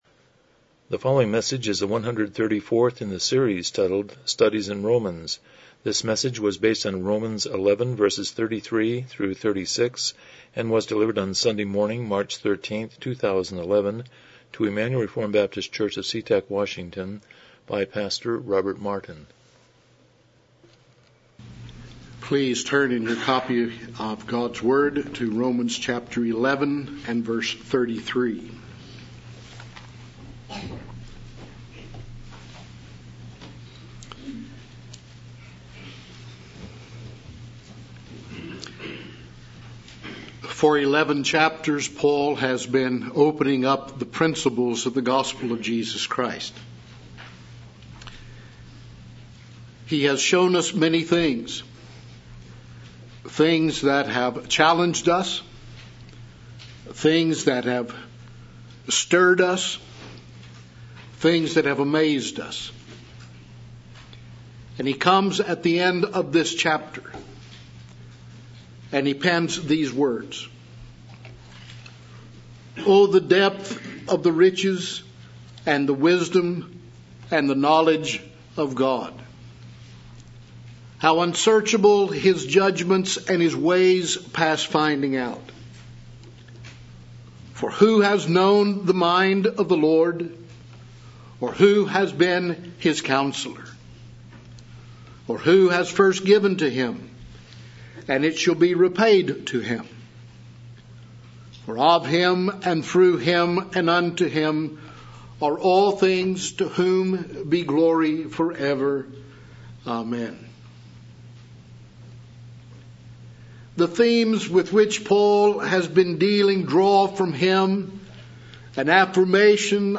Romans 11:33-36 Service Type: Morning Worship « 124 Chapter 25:1-2 Marriage